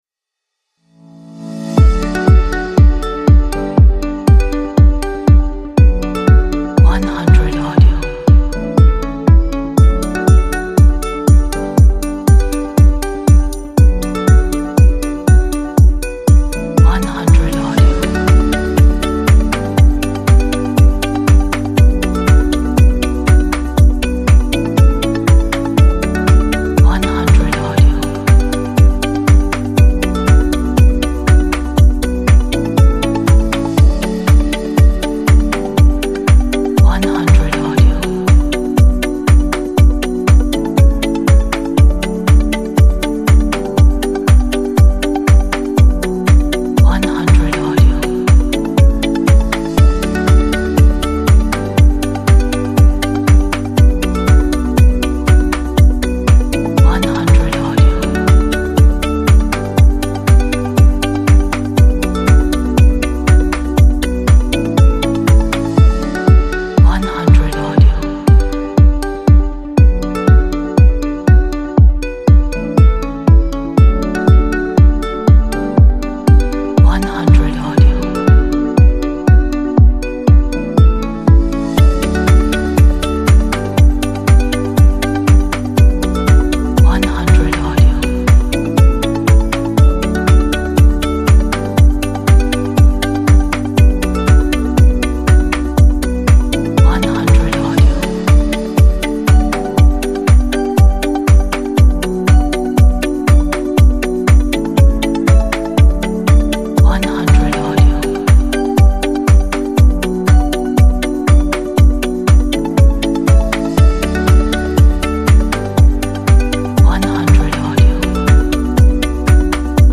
Positive motivational and inspiring corporate track.